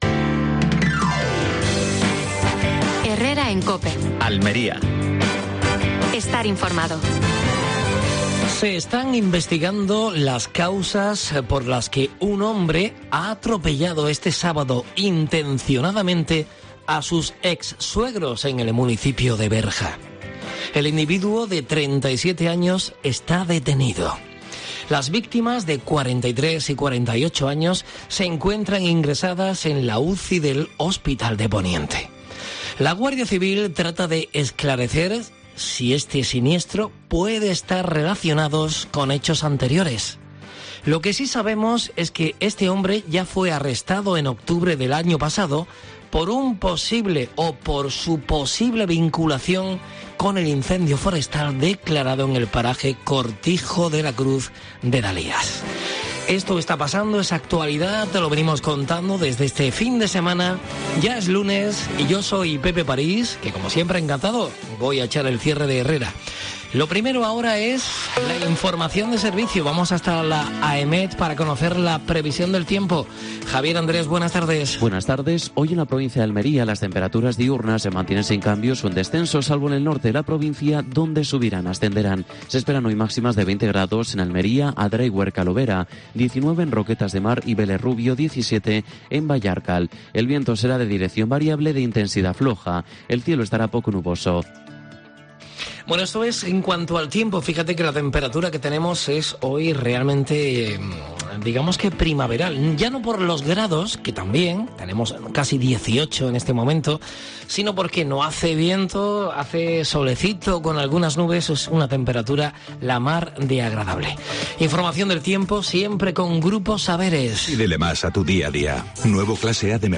AUDIO: Última hora en Almería. Previsión del tiempo. Estado de las carreteras y de la mar.